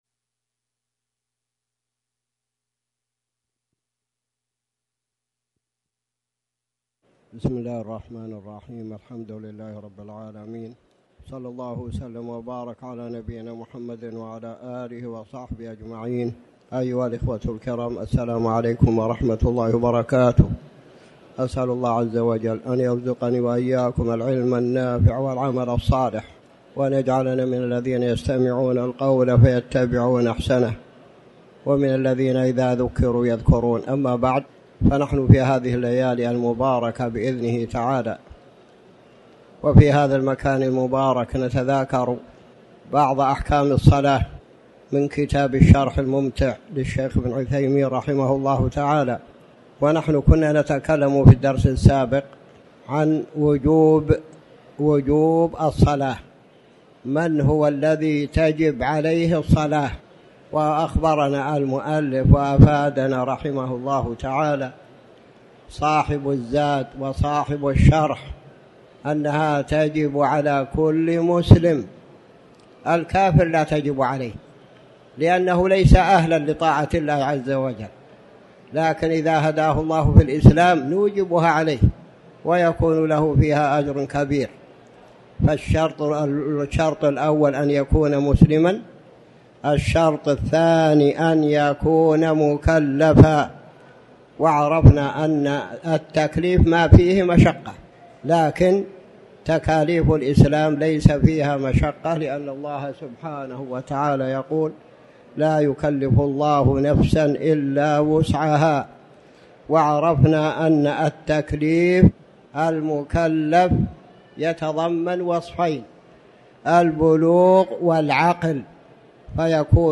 تاريخ النشر ٦ صفر ١٤٤٠ هـ المكان: المسجد الحرام الشيخ